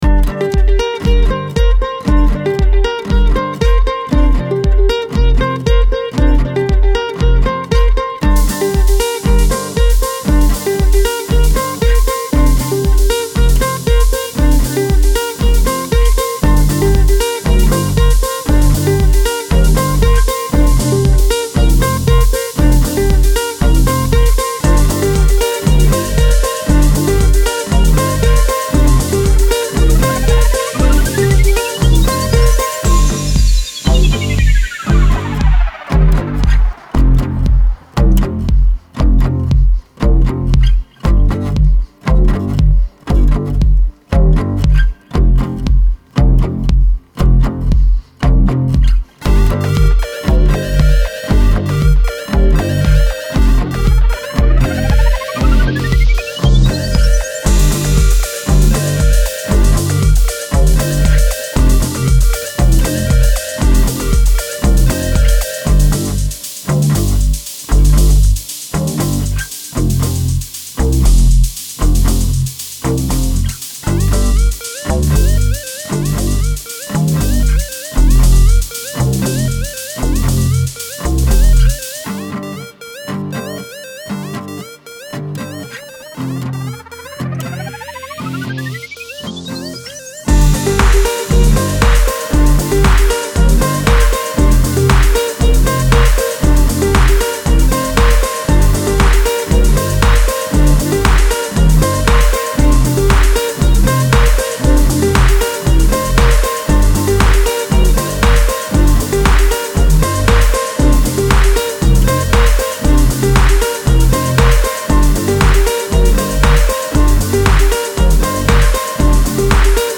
Style Style EDM/Electronic
Mood Mood Cool, Relaxed
Featured Featured Acoustic Guitar, Bass, Drums +2 more
BPM BPM 117